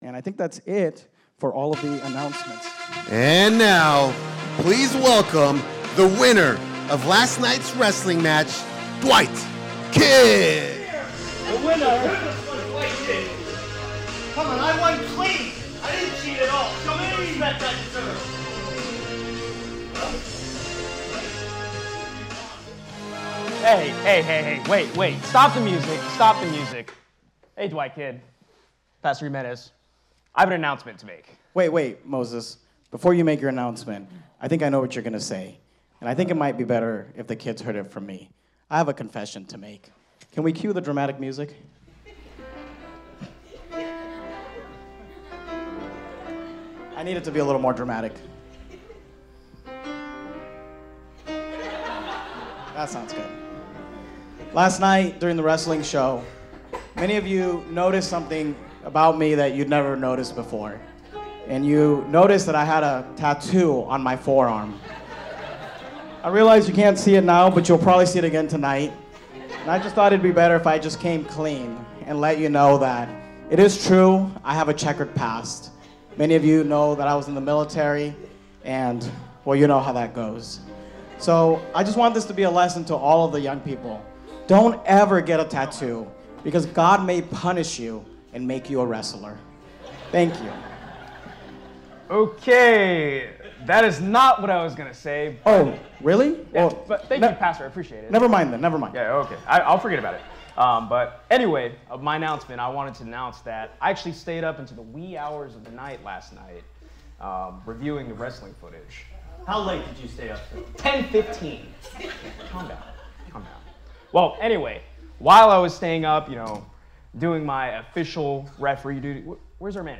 Next Generation Youth Rally 2023